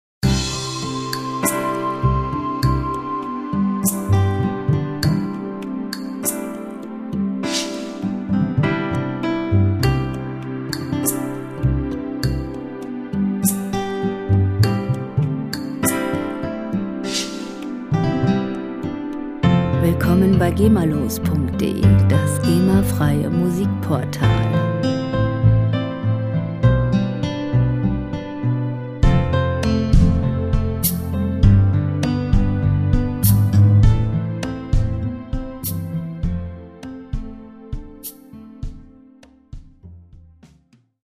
• Pop Ballad